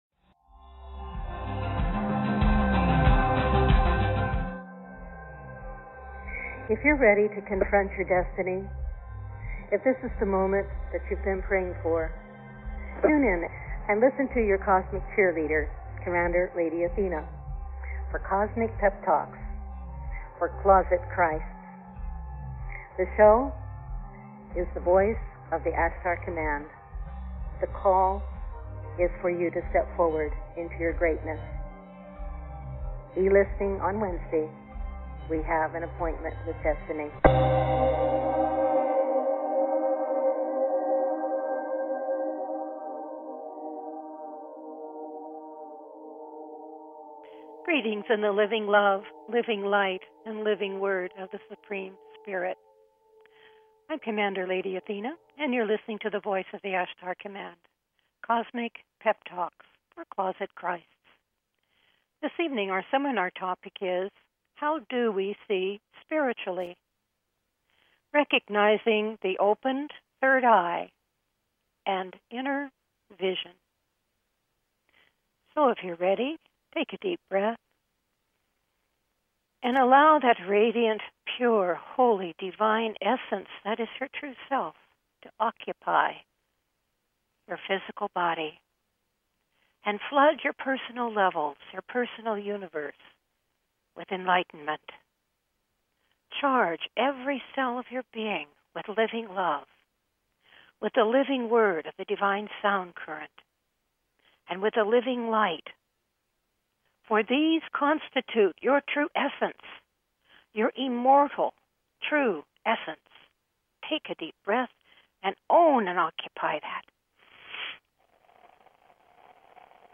Talk Show Episode, Audio Podcast, The Voice of the Ashtar Command-Cosmic Pep Talks For Closet Christs and HOW DO WE SEE SPIRITUALLY?